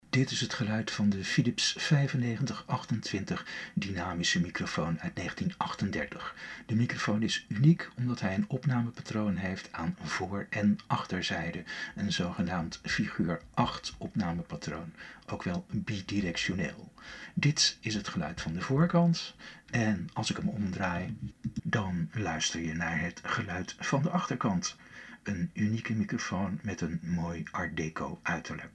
PHILIPS 9528 BI-DIRECTIONELE DYNAMISCHE MICROFOON
De reproductie van zowel muziek als spraak is duidelijk en niet vervormd.